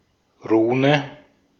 Ääntäminen
US : IPA : [ˈlɛt.ɚ]